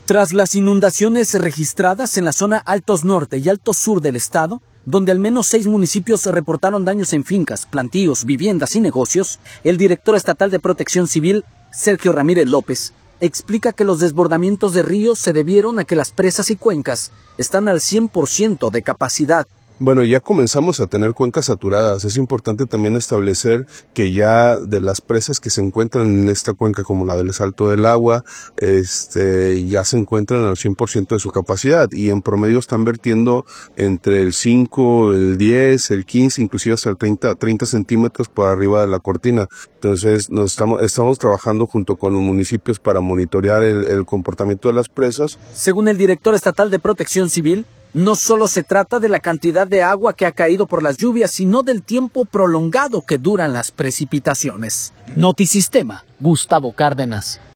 Tras las inundaciones registradas en la zona de Altos Norte y Altos Sur del Estado, donde al menos seis municipios reportaron daños en fincas, plantíos, viviendas y negocios, el director estatal de Protección Civil, Sergio Ramírez López, explica que los desbordamientos de ríos se debieron a que las presas y cuencas están al 100 por ciento de capacidad.